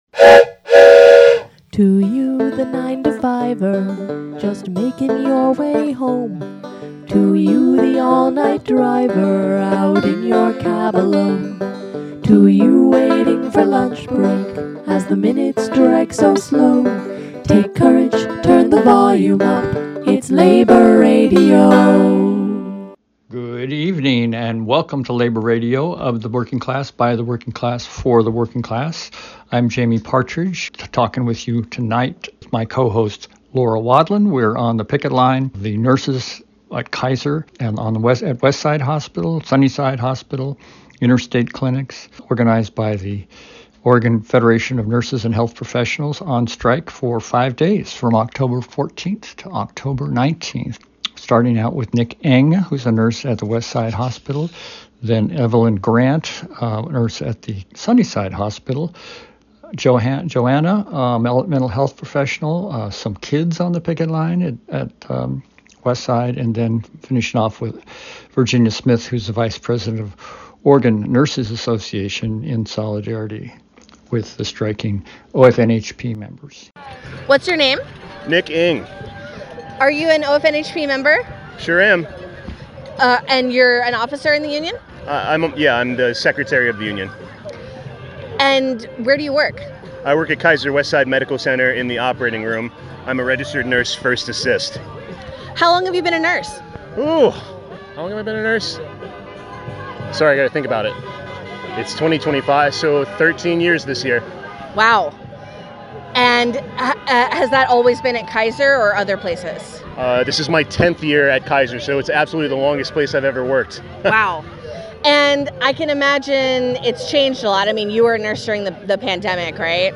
In a series of short interviews on the Kaiser Workers Strike Line, October 14-19, we hear from nurses, a mental health professional, caring kids, and a nurse from Providence on the line in solidarity. Staffing, wages, schedules and control over the work are chronic issues that may take another, open-ended strike to resolve.